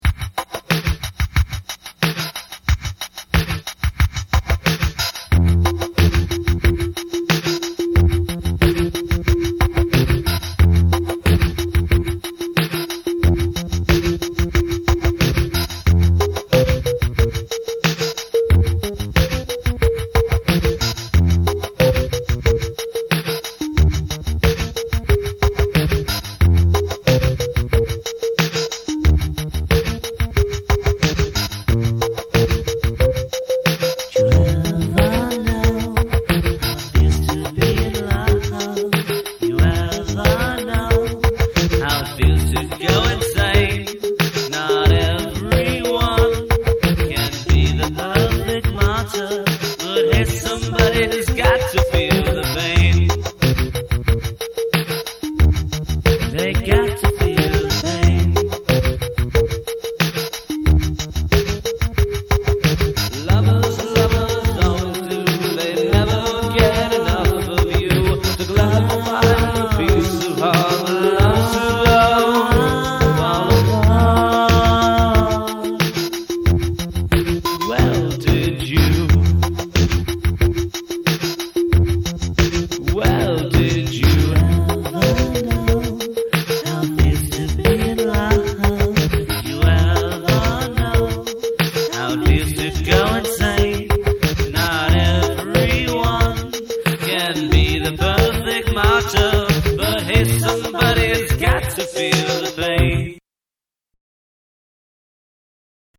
• (D) Sang Lead Vocals
• (E) Sang Backing Vocals
• (F) Played Drums
• (H) Played Keyboards